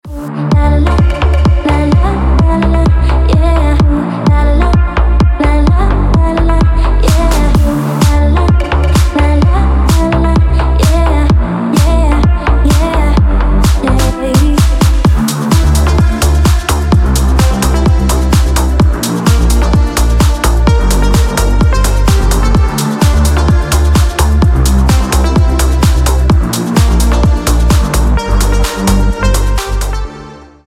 • Качество: 320, Stereo
deep house
женский голос
Electronic
качающие
slap house
Танцевальный house рингтон